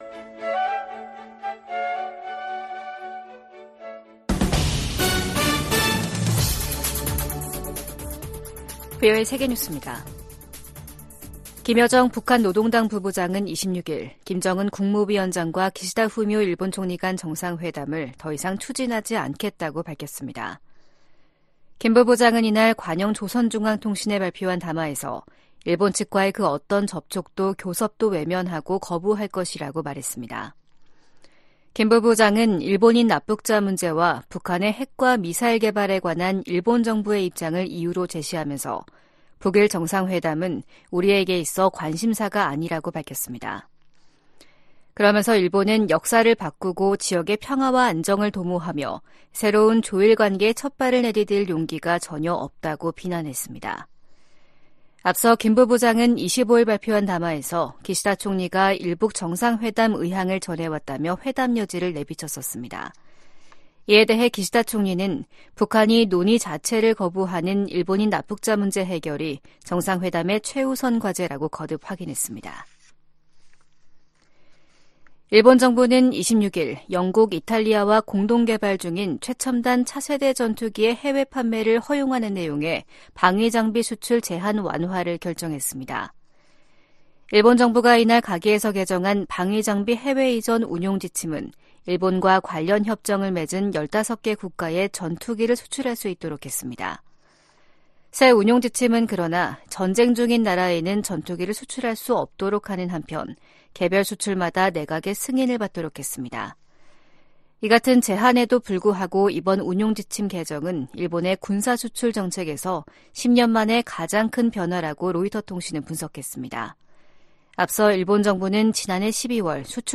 VOA 한국어 아침 뉴스 프로그램 '워싱턴 뉴스 광장'입니다. 조 바이든 미국 대통령이 서명한 2024회계연도 예산안에 북한 관련 지출은 인권 증진, 대북 방송, 북한 내 미군 유해 관련 활동이 포함됐습니다. 미 국방부가 일본 자위대의 통합작전사령부 창설 계획에 대한 지지를 표명했습니다. 남북한이 다음달 군사정찰위성 2호기를 쏠 예정으로, 우주경쟁에 돌입하는 양상입니다.